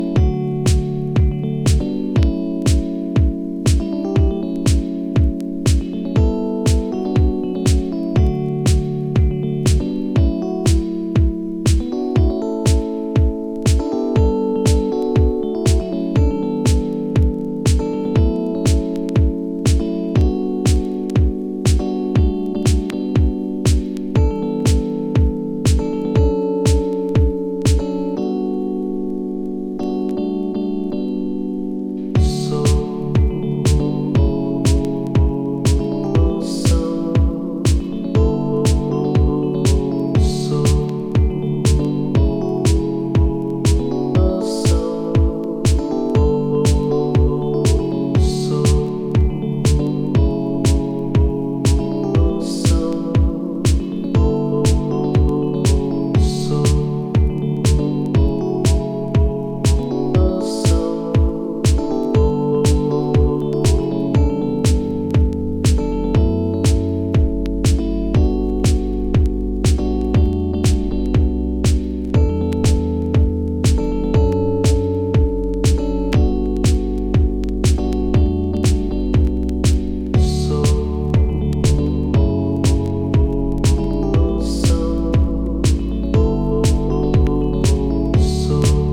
ElectroとHouseのいいとこをMixさせたようなサウンド。Acid風味もグー！
独特のメローさがたまらないです。